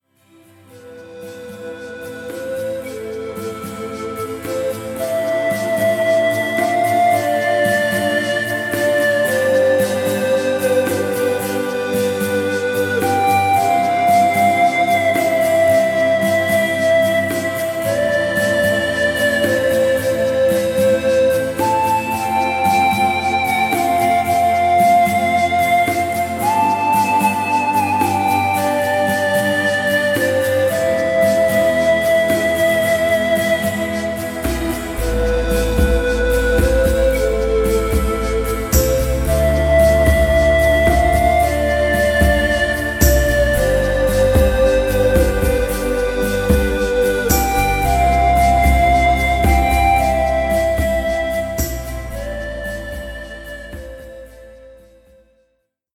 Naturgeräusche sind auch mit an Bord.